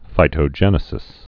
(fītō-jĕnĭ-sĭs)